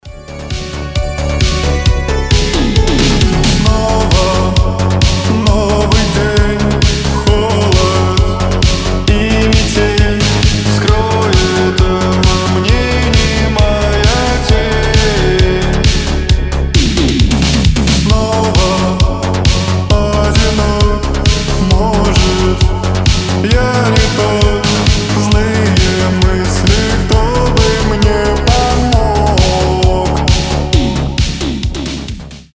поп , рок , грустные , indie rock , атмосферные , new wave
synthwave